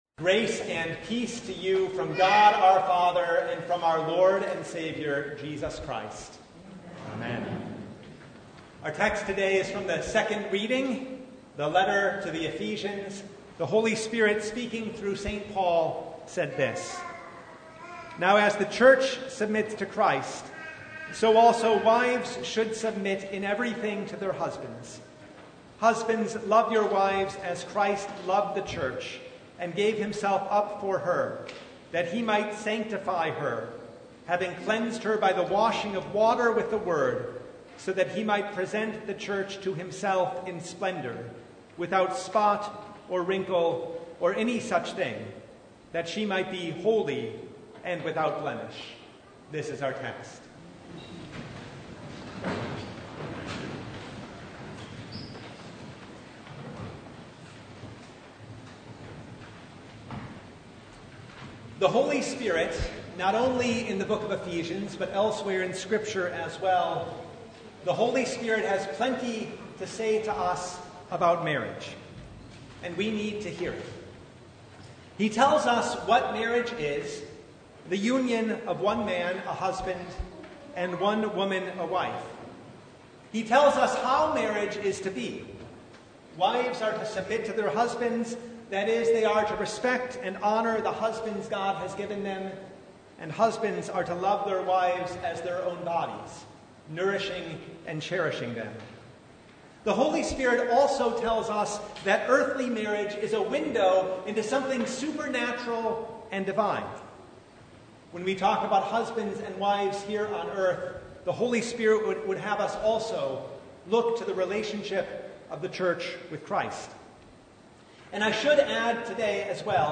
Ephesians 5:22-33 Service Type: Sunday Marriage is a window into the relationship of Christ and the Church.